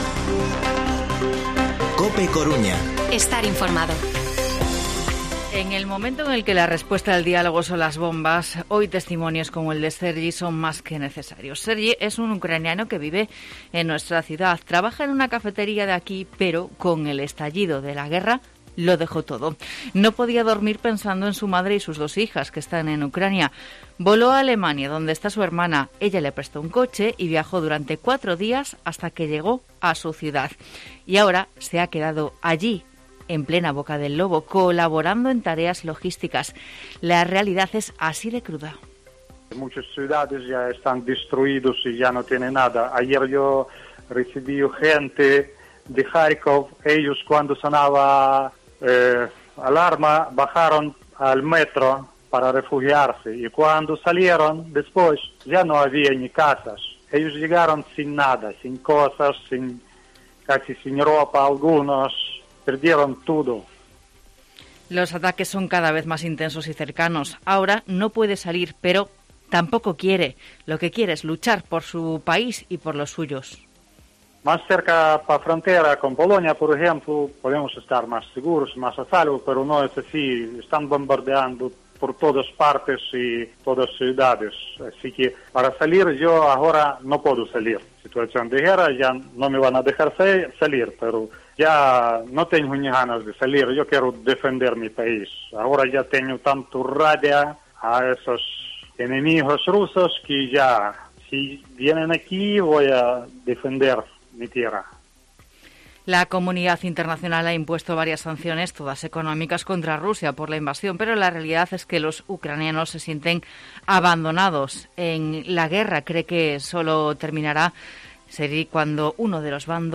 Informativo Mediodía COPE Coruña jueves, 3 de marzo de 2022 14:20-14:30